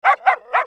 Translation of her bark:
bichon_frise_puppy.mp3